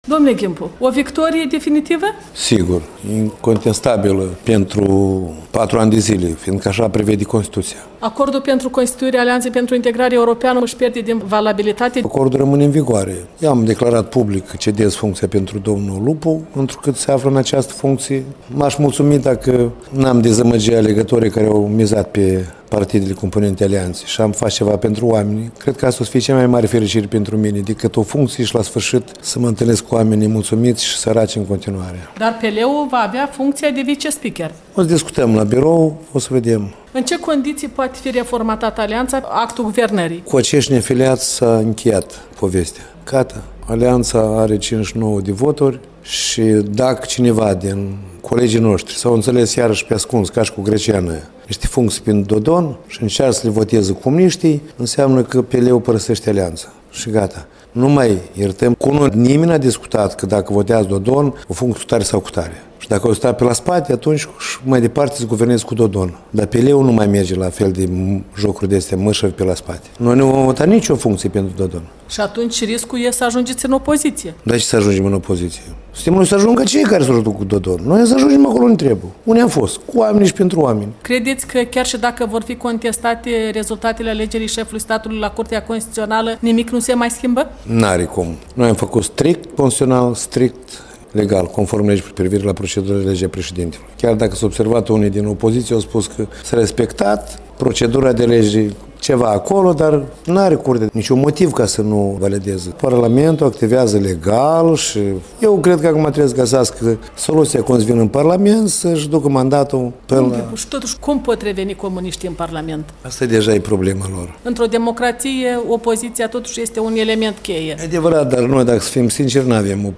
Interviu cu liderul PL Mihai Ghimpu.